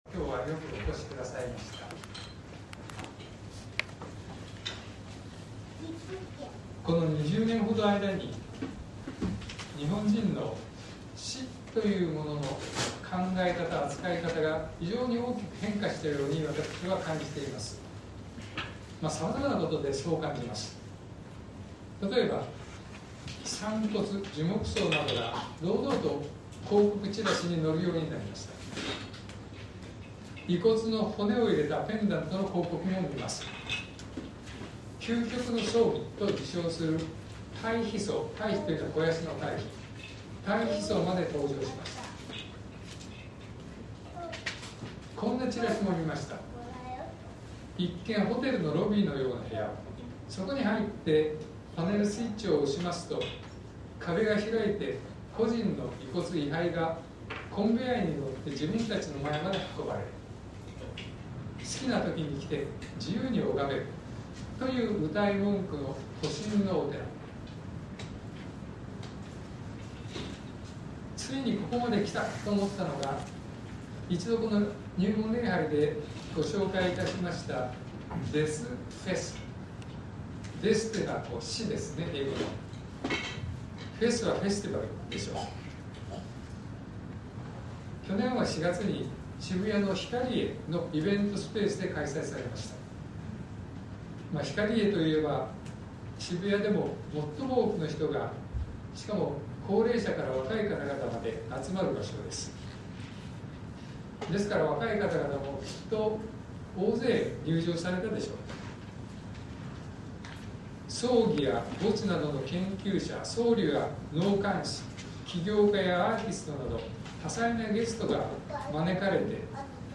2025年01月19日朝の礼拝「死後、どうすごす？」東京教会
説教アーカイブ。